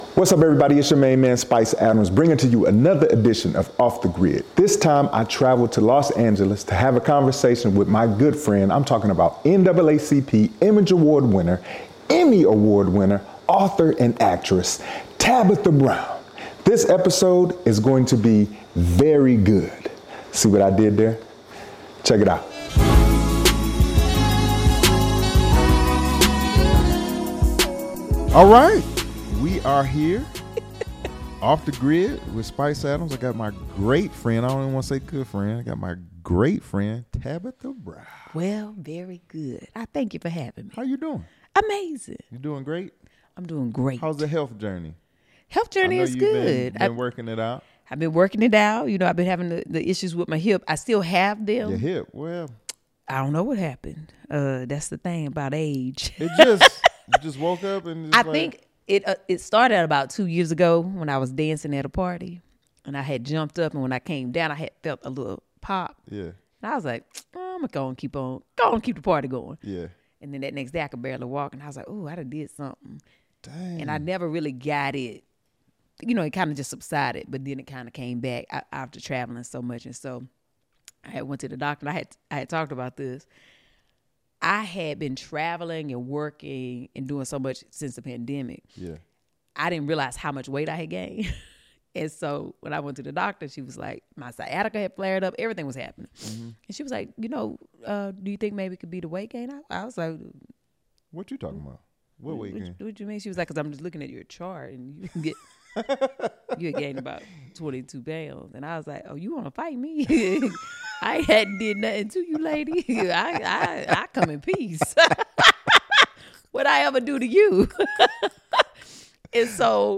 Spice travels to LA to have a conversation with Emmy and NAACP Image Award-winning author and actress, Tabitha Brown. The pair discuss Tabitha's journey to Hollywood, how she became a vegan, the balancing of family and career, and much more.